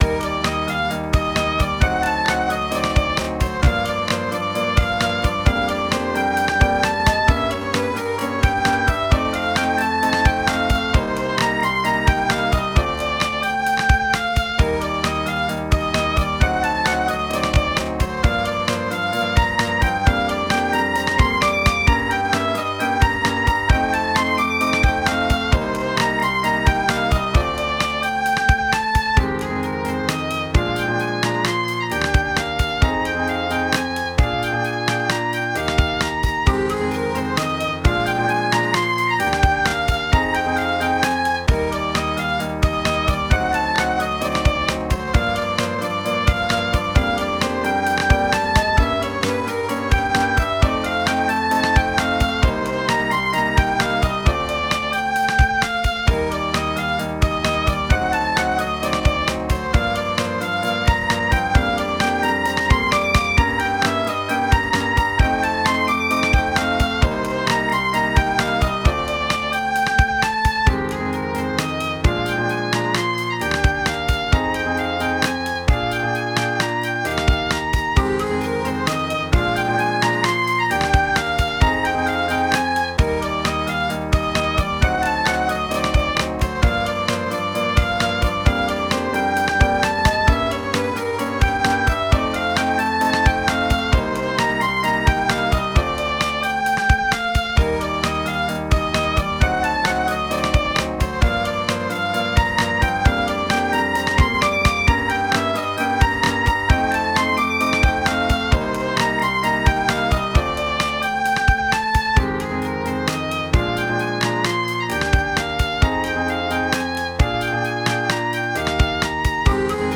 BGM
軽やかな足取りで、新しい一日を踏み出すためのモーニングBGM。